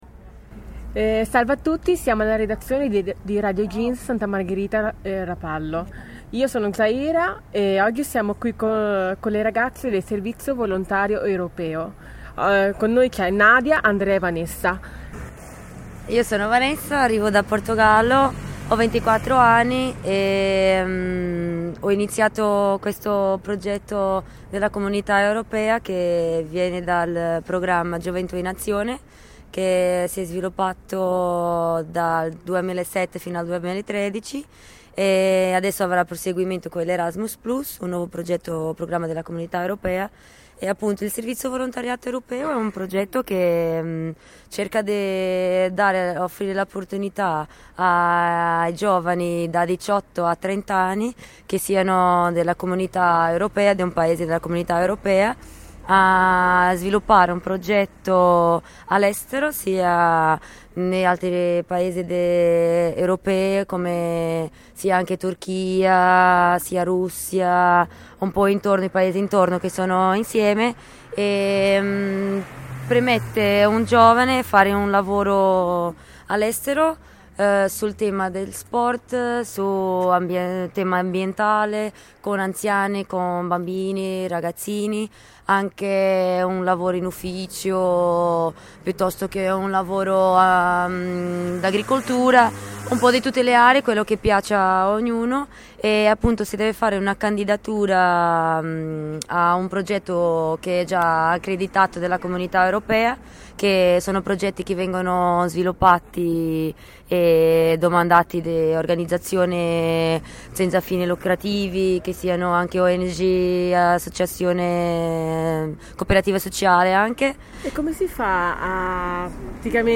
Intervista a tre ragazze del Servizio Volontario Europeo. Interessante esperienza, raccontata ai microfoni della nostra redazione, di scambi culturali e lavorativi all'interno dell'Europa.